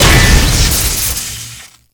bladeslice2.wav